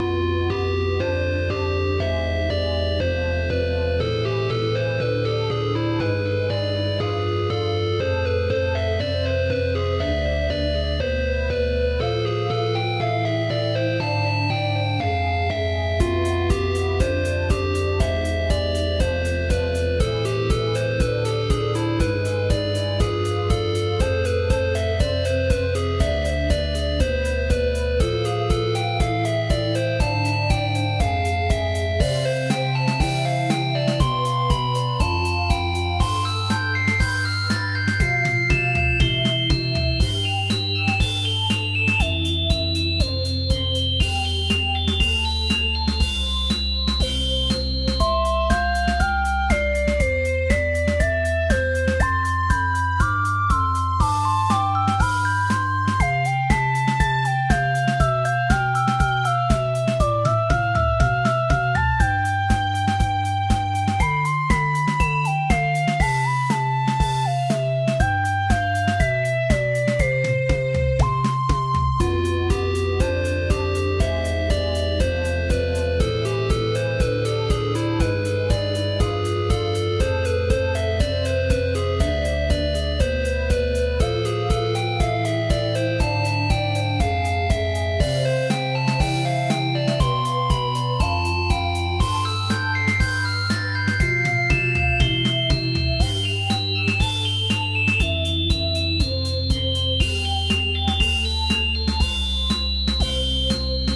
- Add ambiance tracks for entrepot, atelier and cour zones